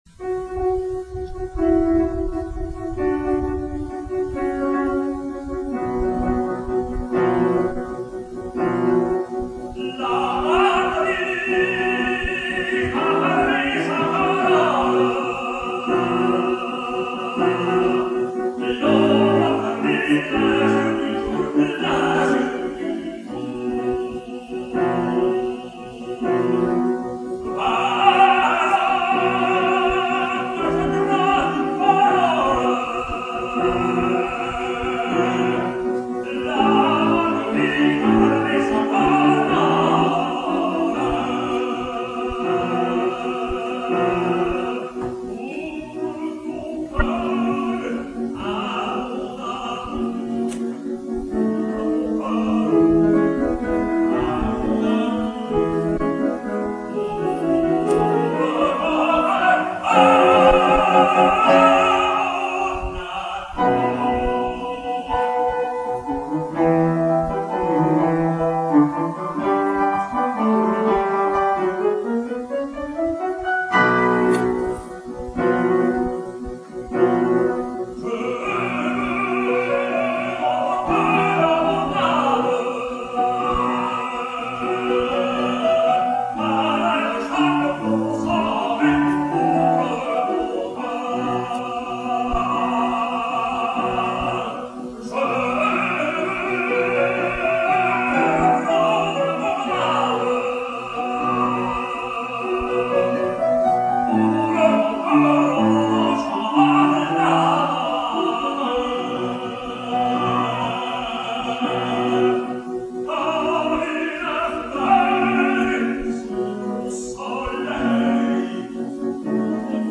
Liederabend
Nicolai Gedda, Tenor
Klavier